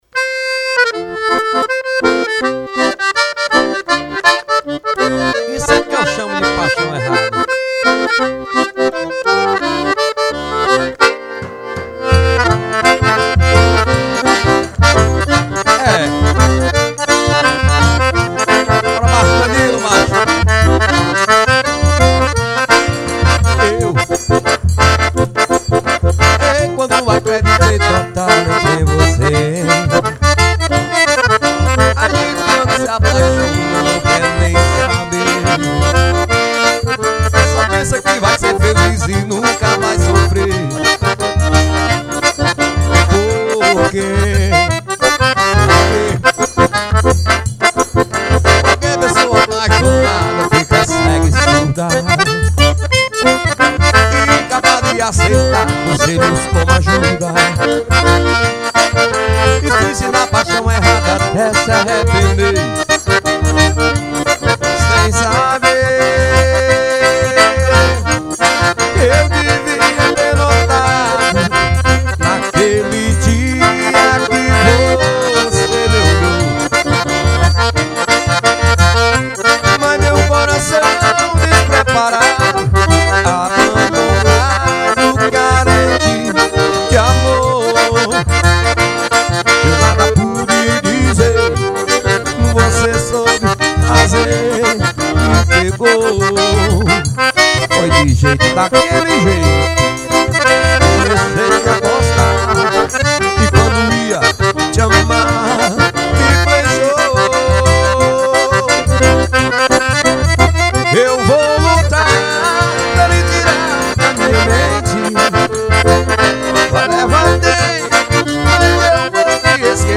Composição: vaneirão.